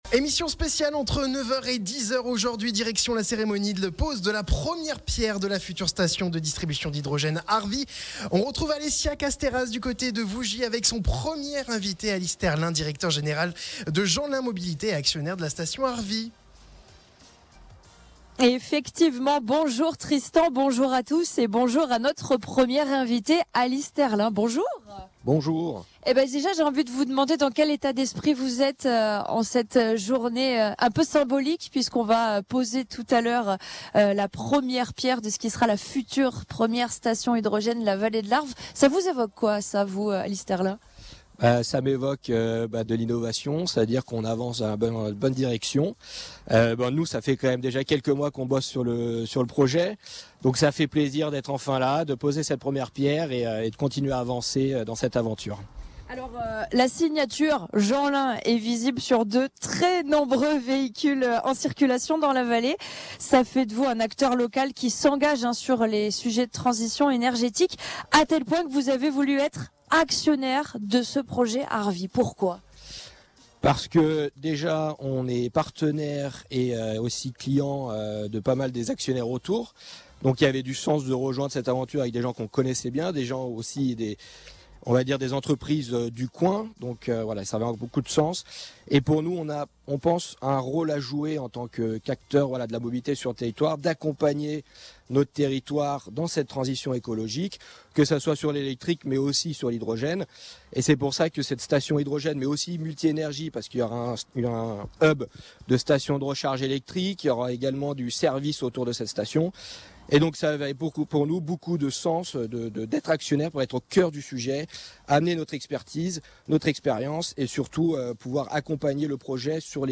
Ce jeudi 27 juin, Radio Mont Blanc était en direct de Vougy pour une émission spéciale à l’occasion de la pose de la première pierre de la future station multi-énergies Arv’Hy.